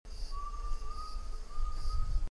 UNDULATED TINAMOU Crypturellus undulatus
A haunting, monotone series of drawn out whistles.
Song recorded Mbaracayú Biosphere Reserve, Departamento Canindeyú